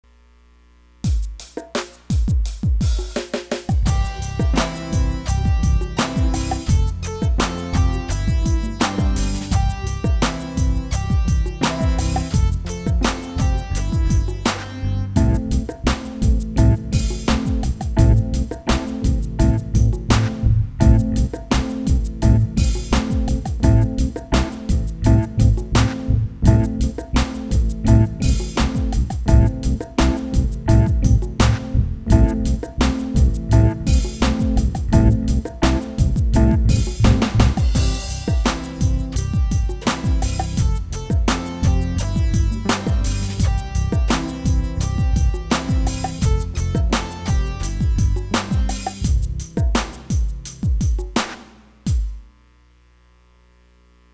Here is the accompaniment track if you want to perform your rap at home.